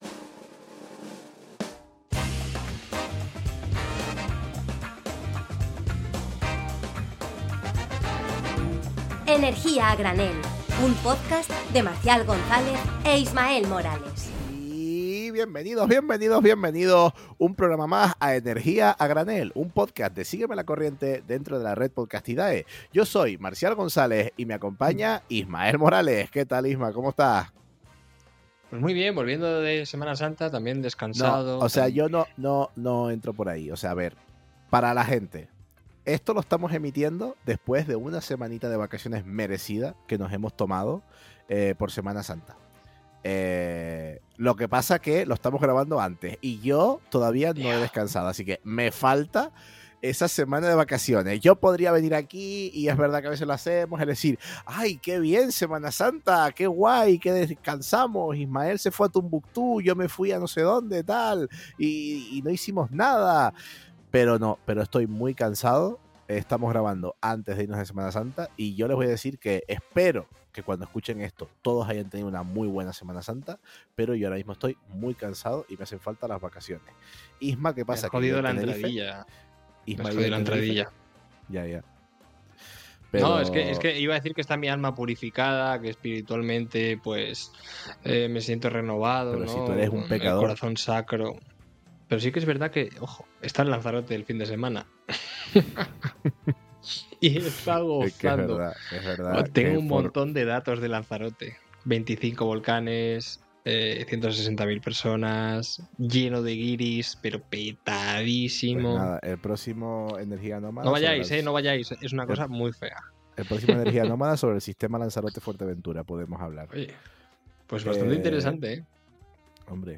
¿Qué pasa con la nuclear en España?. Entrevistamos